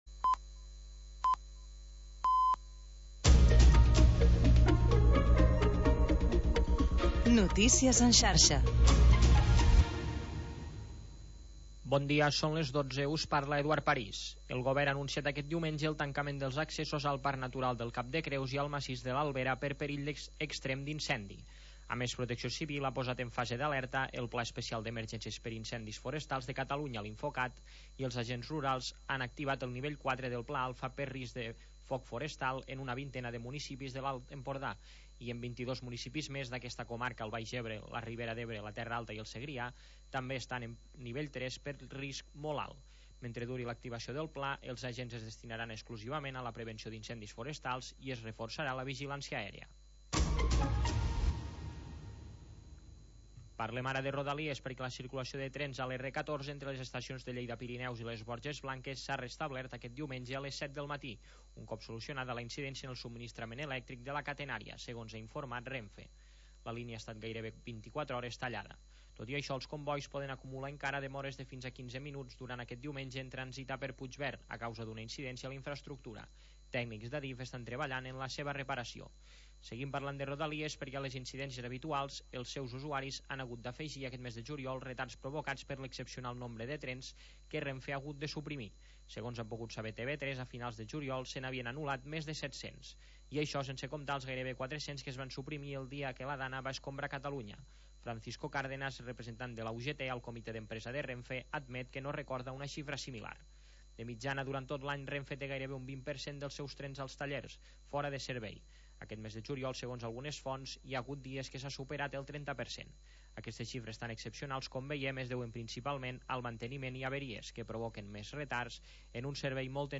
Música nostàlgica dels anys 50, 60 i 70